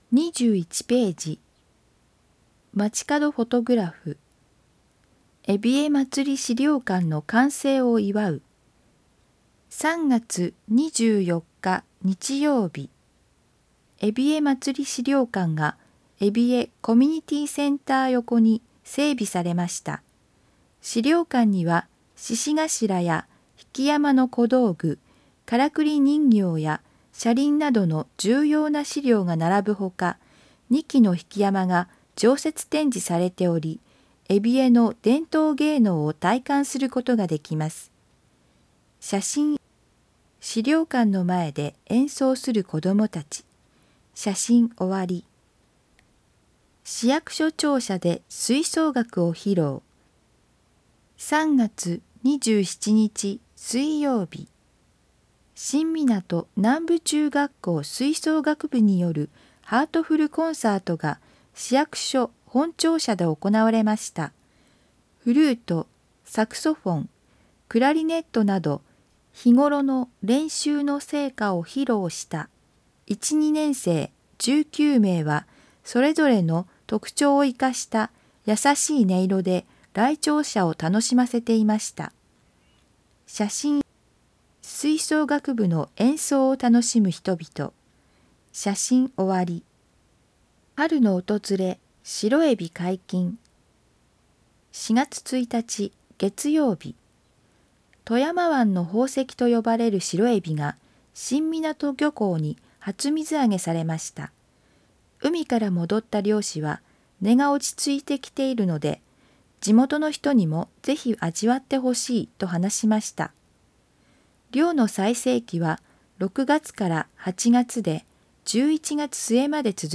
広報いみず 音訳版（令和元年５月号）｜射水市